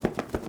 Babushka / audio / sfx / Animals / SFX_Duck_Wings_06.wav
SFX_Duck_Wings_06.wav